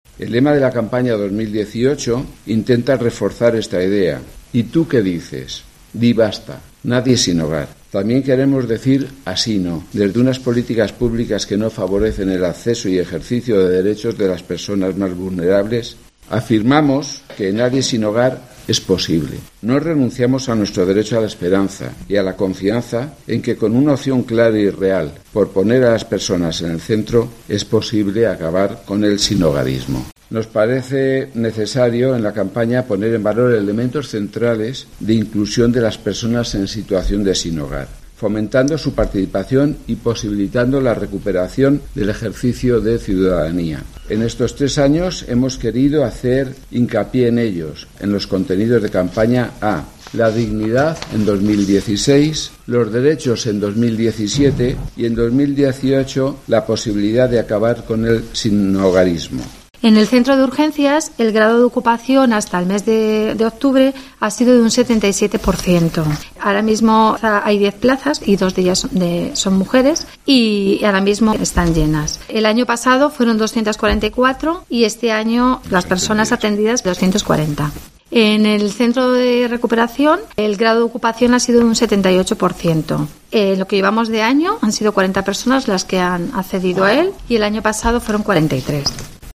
Informativos Guadalajara